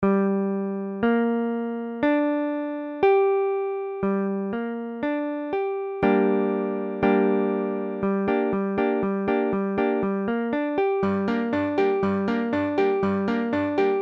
Tablature Gm.abcGm : accord de Sol mineur
Mesure : 4/4
Tempo : 1/4=60
Forme fondamentale : tonique quinte octave tierce mineure
Sol mineur Barré III (sol case 3 ré case 5 doigt 3 sol case 5 doigt 4 si bémol case 3 ré case 3 sol case 3)